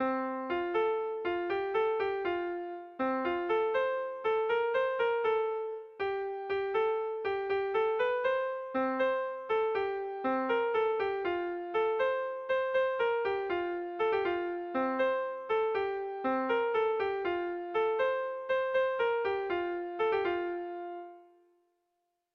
Gabonetakoa
ABDEFG